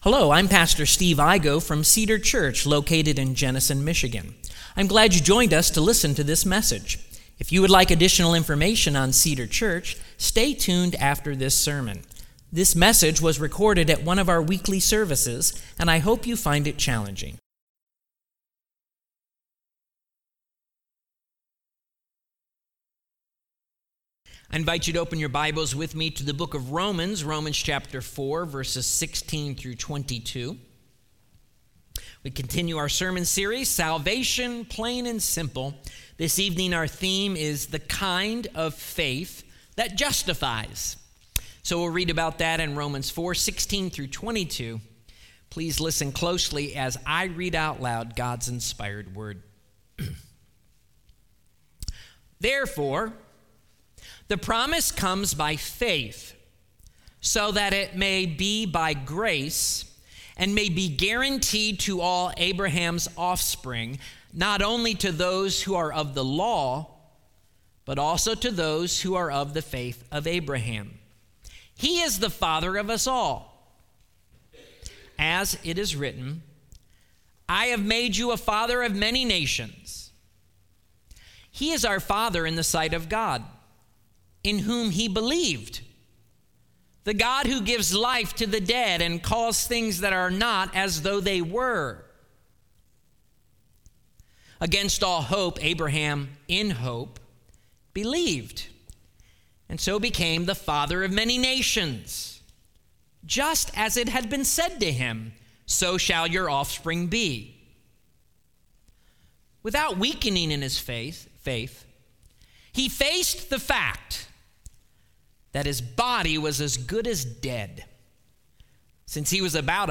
Current Sermon The Kind Of Faith That Justifies - Romans 4:16-22 Salvation.